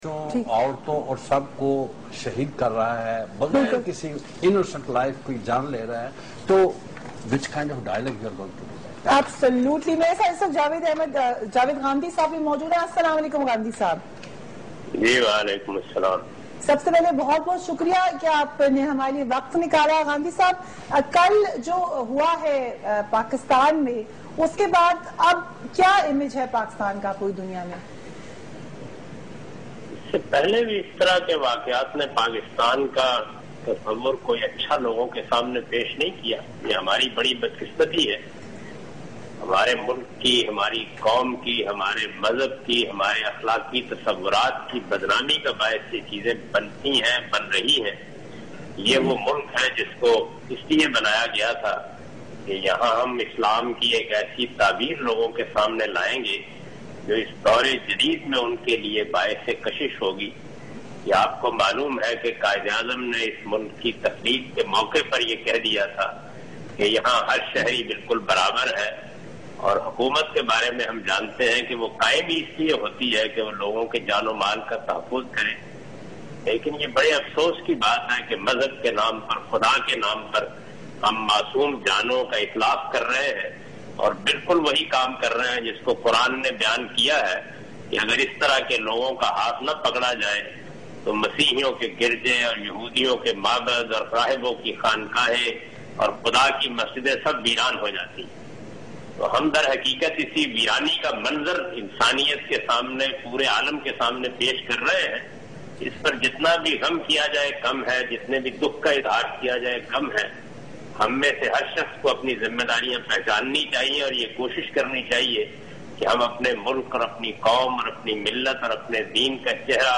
Category: TV Programs / Questions_Answers /
Javed Ahmad Ghamidi is commenting on Peshawar Church Attack In 8pm with Fareeha on Waqat Tv.